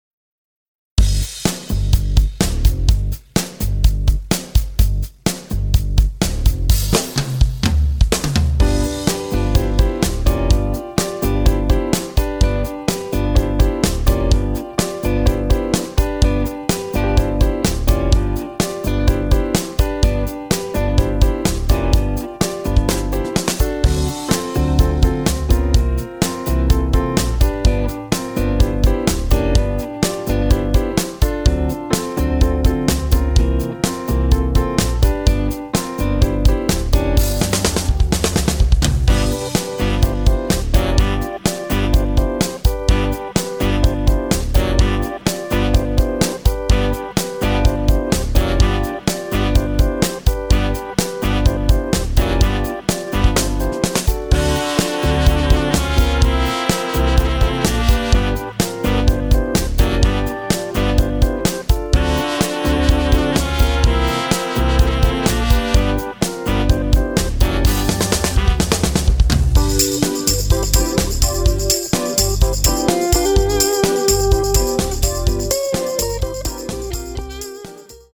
Square Dance Music
(Patter)